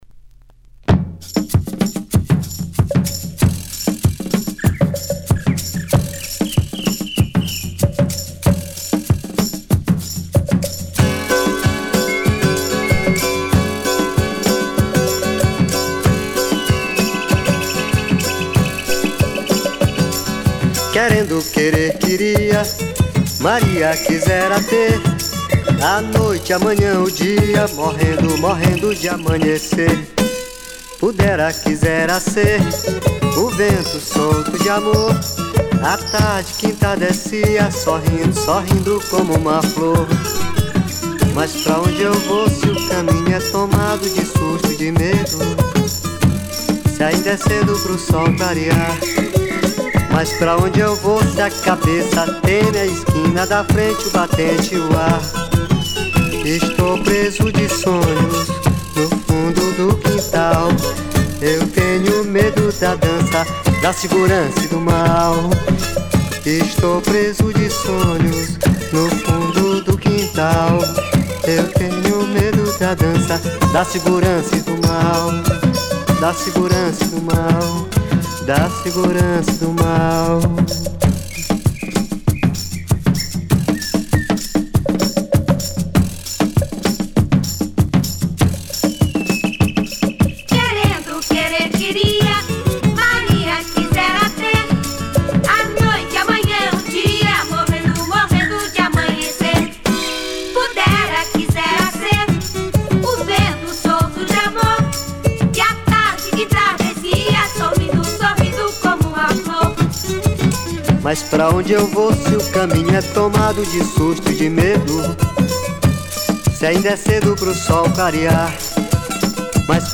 00:00:00   Mpb